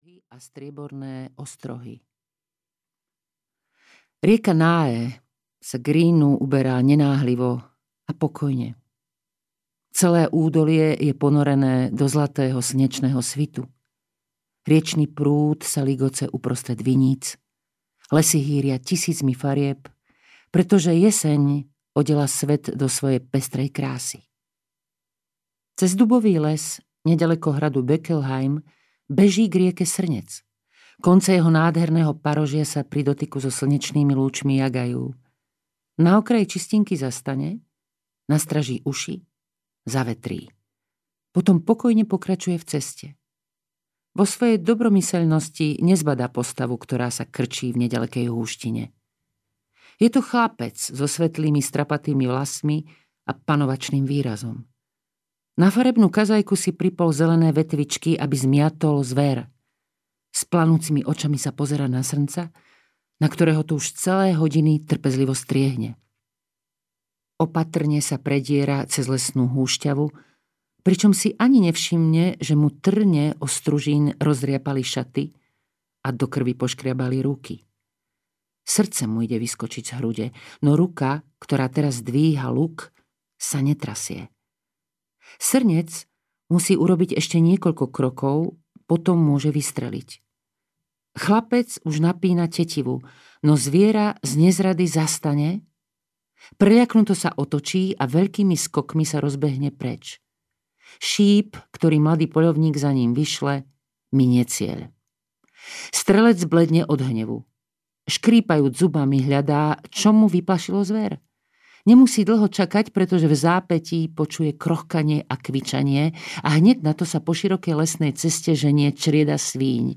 Živé svetlo audiokniha
Ukázka z knihy